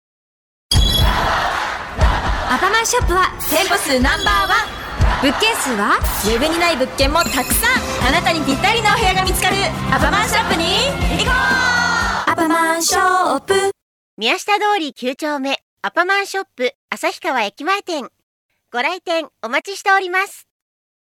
街頭放送CMの専門家
音の広告　街頭放送